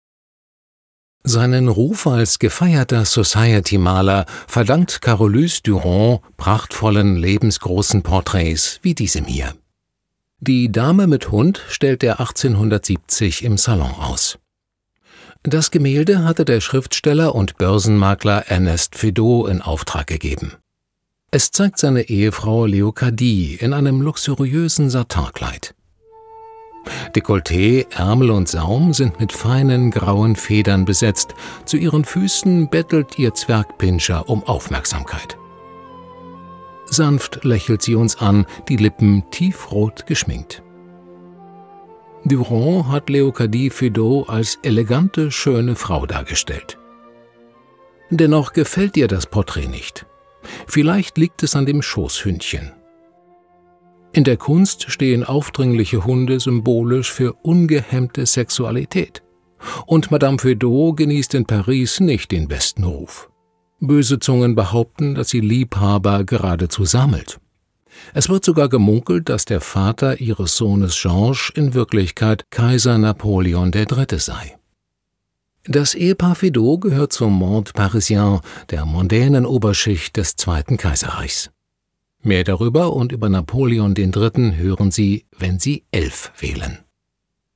sehr variabel
Mittel plus (35-65)
Audioguide